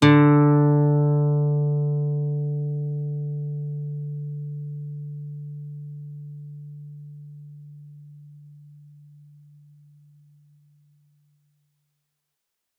guitar-acoustic
D3.wav